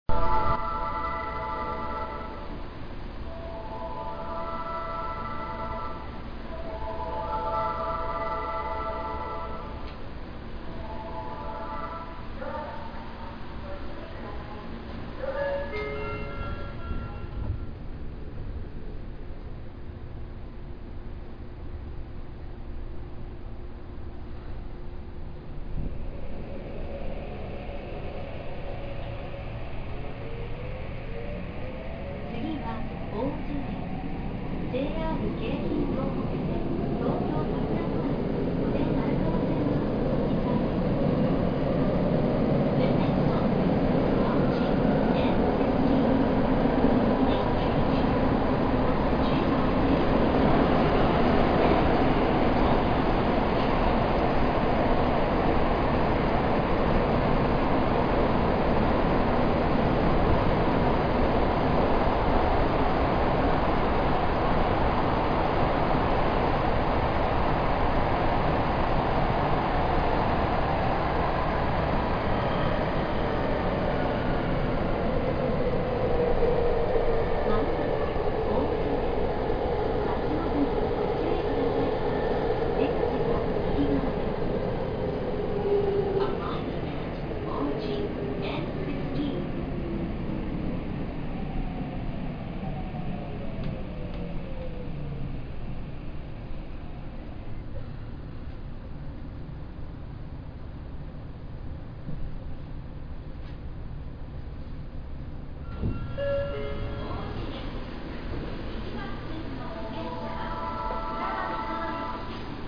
・2000系走行音
【東京メトロ南北線】西ヶ原→王子
基本的に走行音は東京メトロ05系の8次車と同様で、三菱のIGBT。